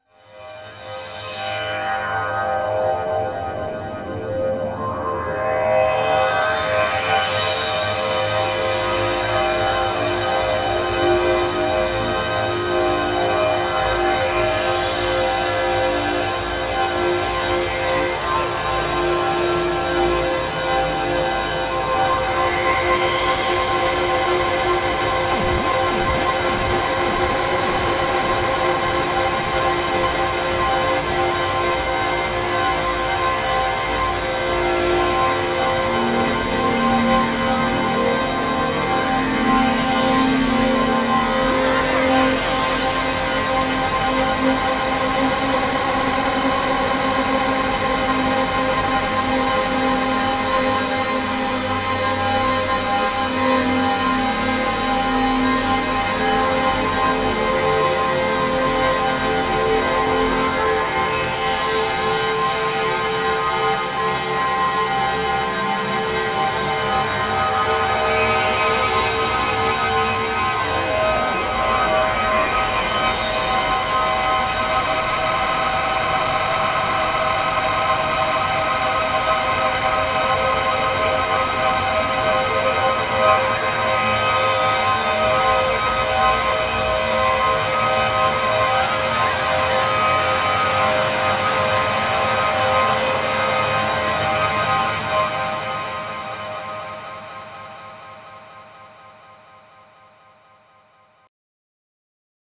Guess it largely depends on what you mean by “gritty”, but here are some (poorly played) not-pleasant patches I made this afternoon.
No external FX, played straight from the Super6 into an audio interface (TASCAM Model16, which is pretty clean).